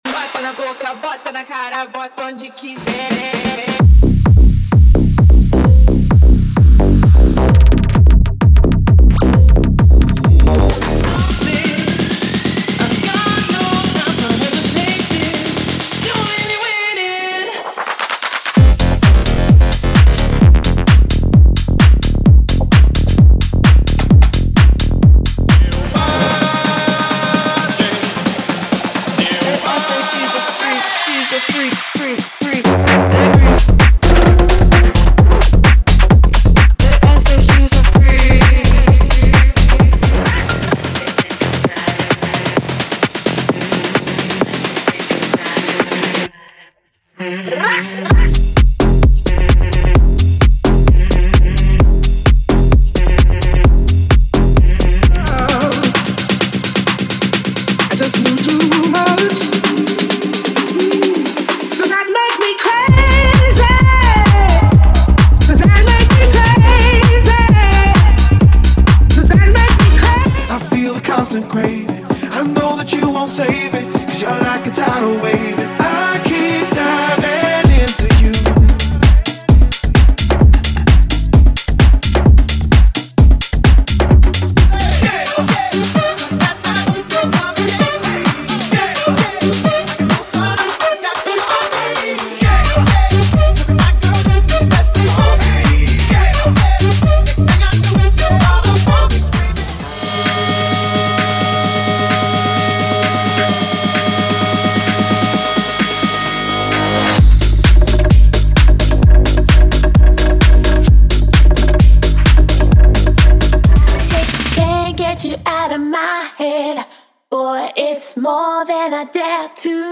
TECH HOUSE = 100 Músicas
Sem Vinhetas